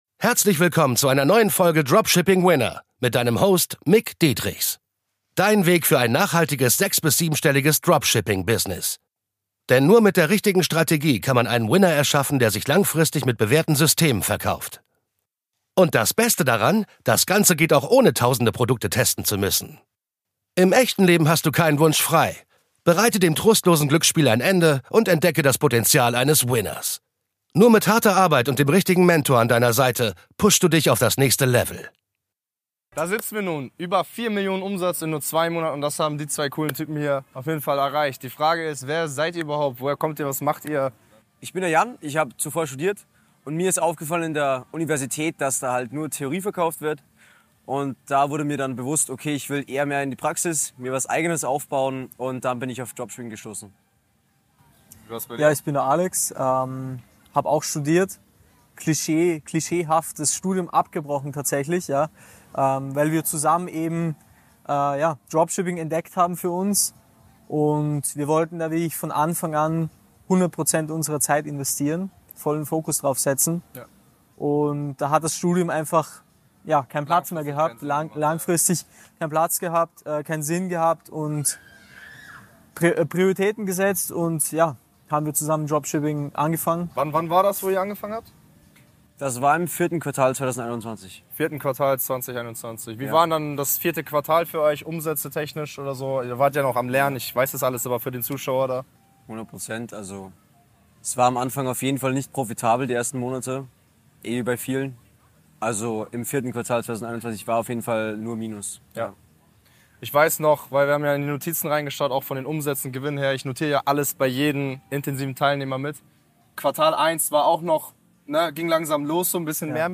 4 Million € Umsatz in 2 Monaten Kundeninterview